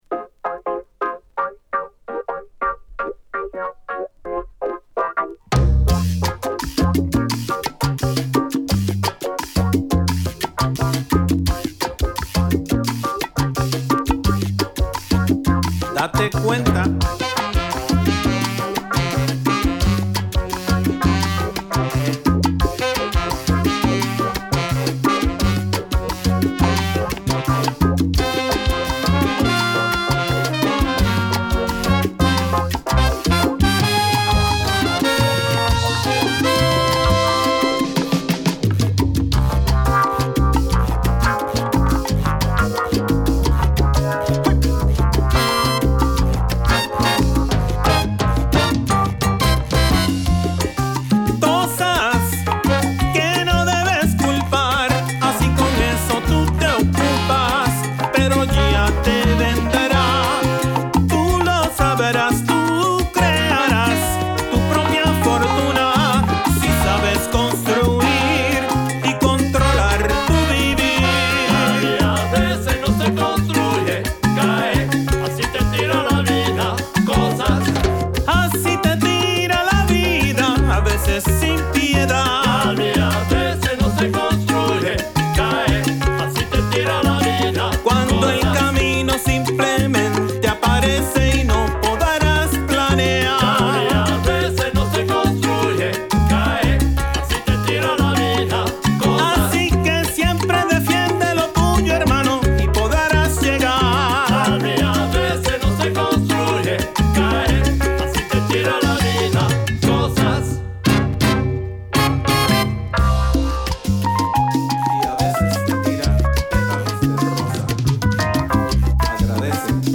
Afro Cuban , B , Latin , Salsa